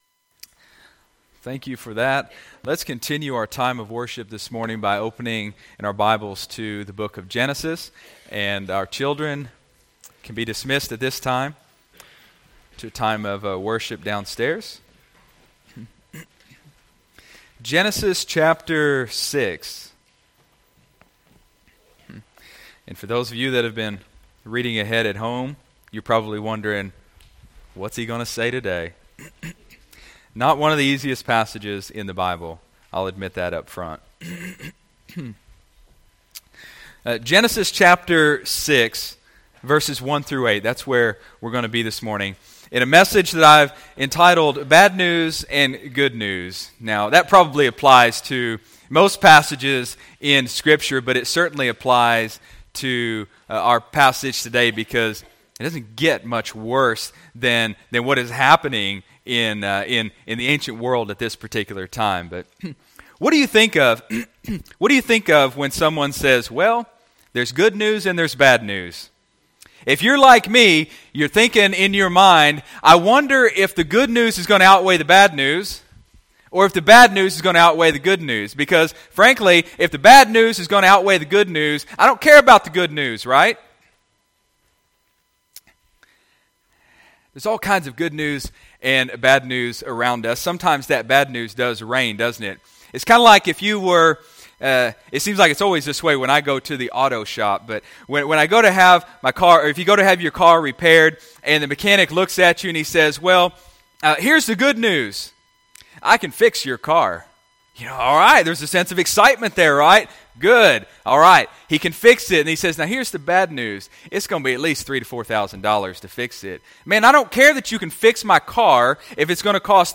Sunday, February 21, 2016 (Sunday Morning Service)